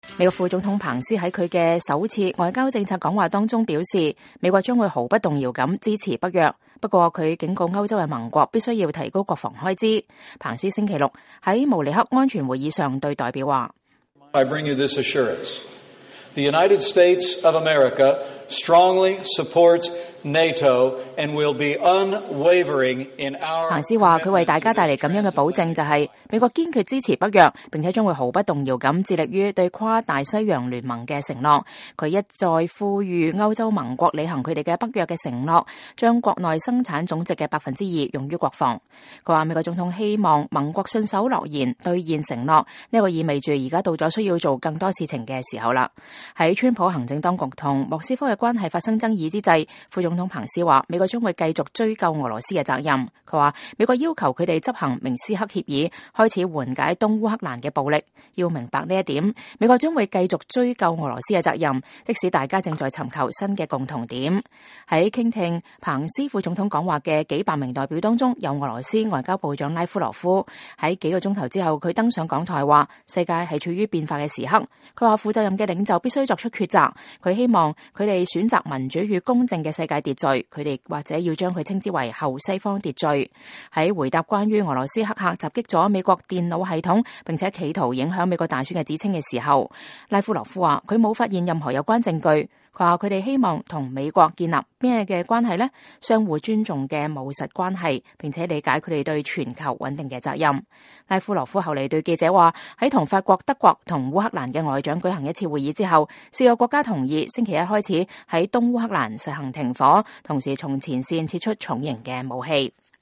美國副總統彭斯在慕尼黑安全會議上講話（2017年2月18日）